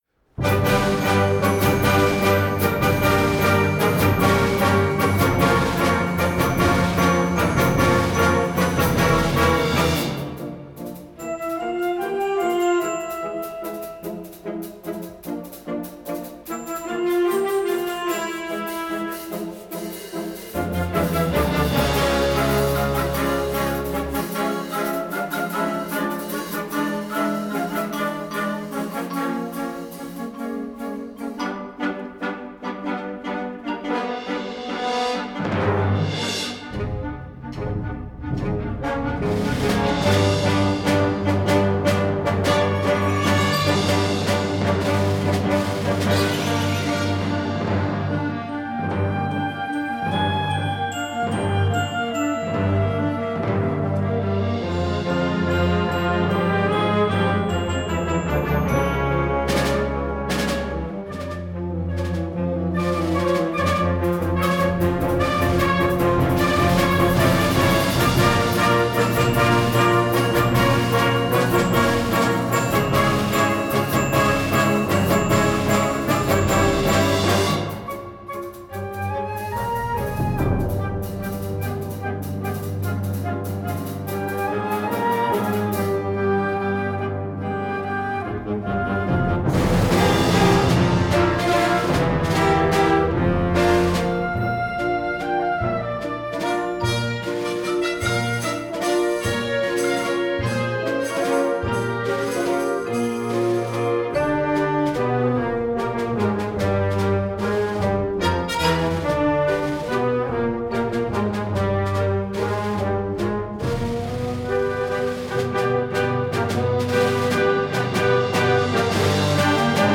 Gattung: Konzertwerk für Jugendblasorchester
Besetzung: Blasorchester
ist ein kühnes und exzentrisches Werk